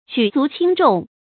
注音：ㄐㄨˇ ㄗㄨˊ ㄑㄧㄥ ㄓㄨㄙˋ
舉足輕重的讀法